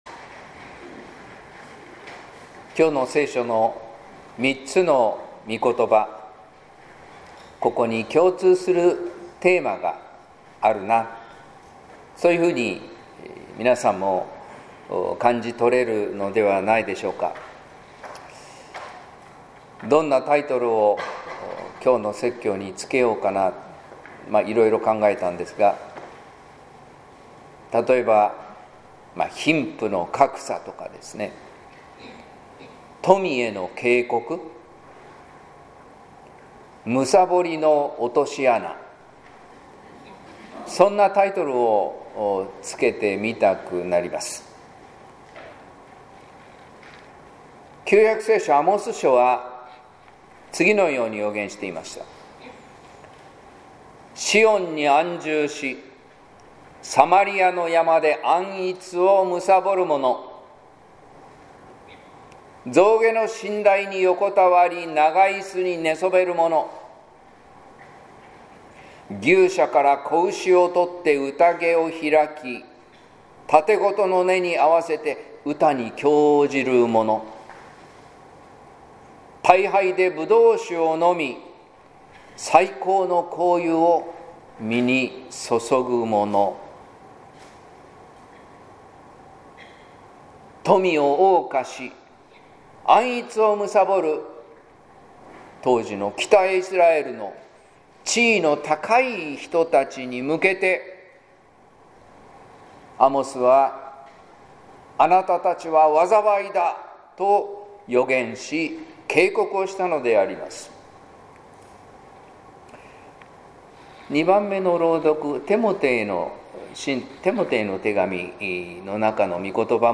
説教「ラザロが受けた祝福」（音声版） | 日本福音ルーテル市ヶ谷教会